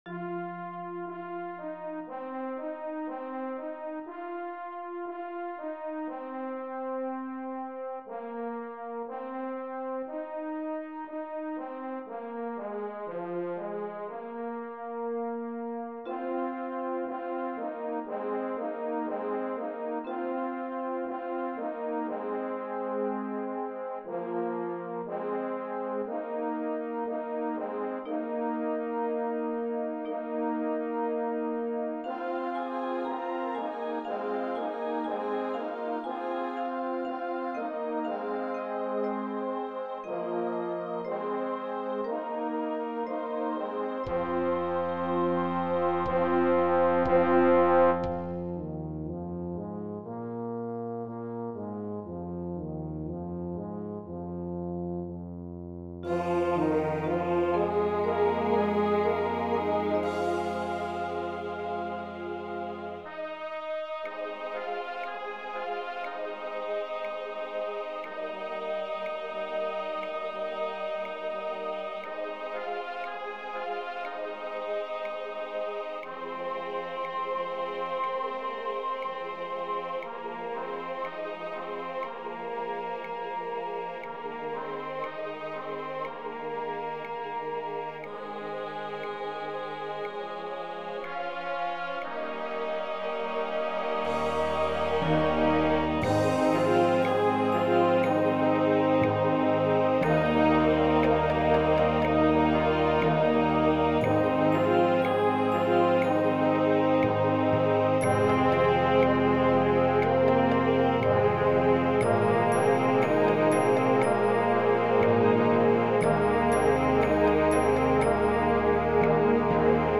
Voicing: 11 Brass and Percussion